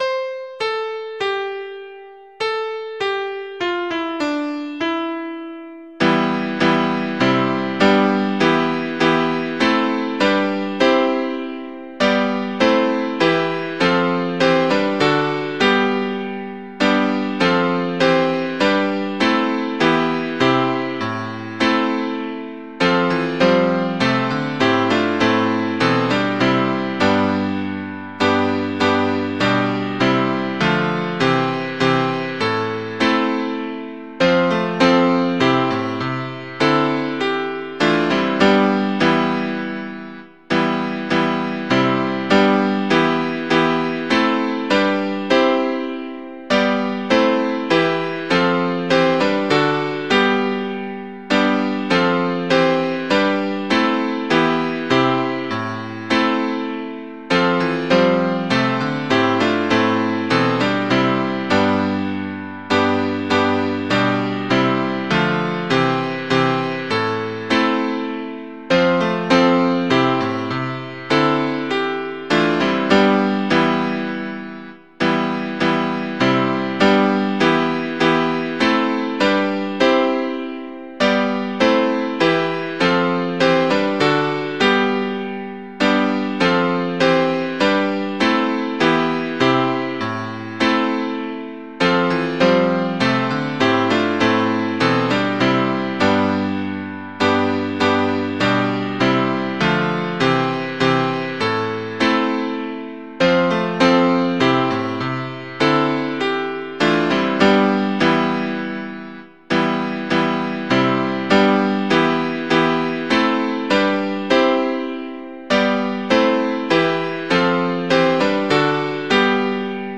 Music: 'Pange Lingua', Proper Sarum Melody, alt.
Mp3 Audio of Tune Abc source